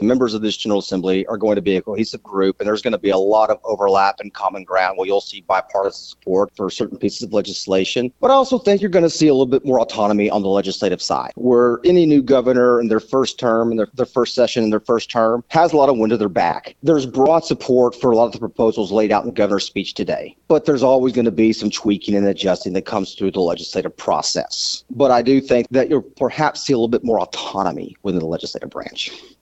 With the start of the 95th legislative session in Arkansas, Scott Flippo, State Senator representing District 23, spoke with KTLO, Classic Hits and The Boot News to share his experiences of the past few days.